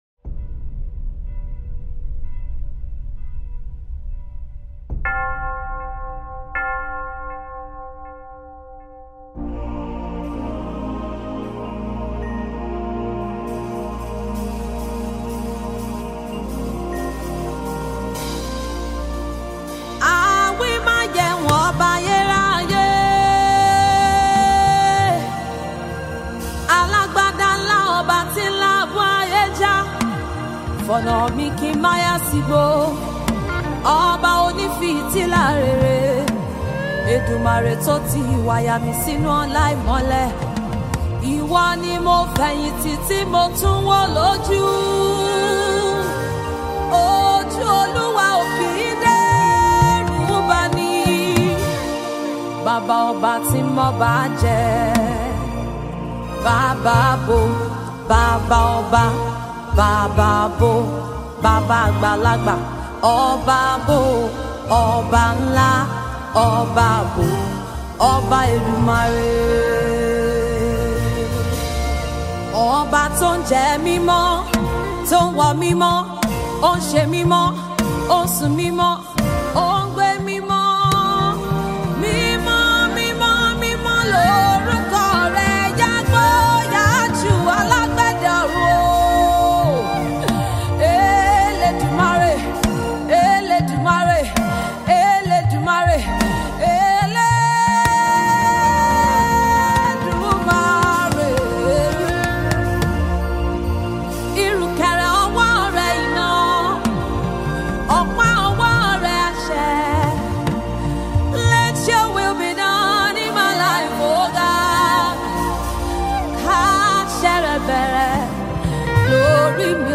praise and worship
MusicNigerianVideoWorship